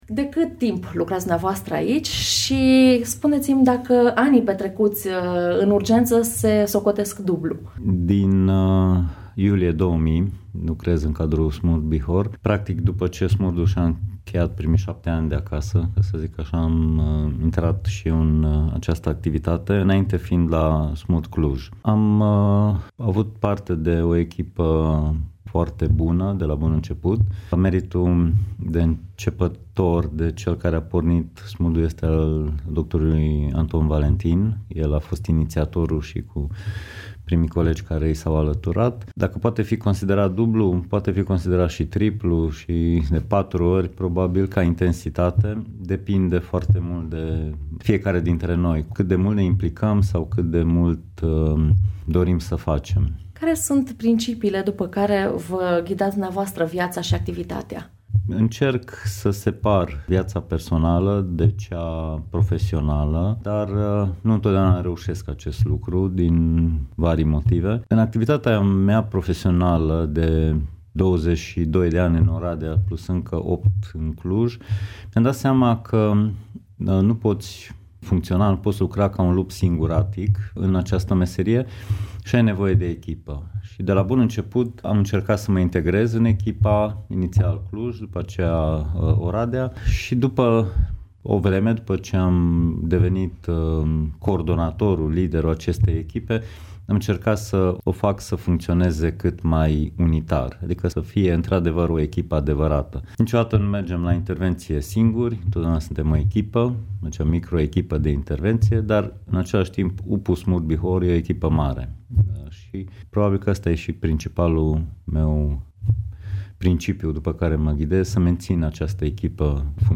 Despre trăirile și cazurile traumatizante ale medicilor de la SMURD - un interviu